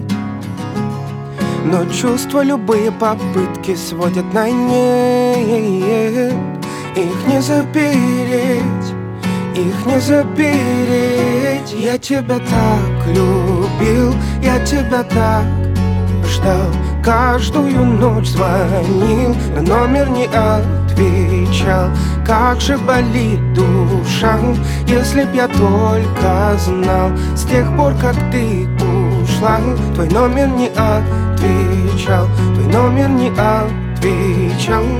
Ambient Electronic Pop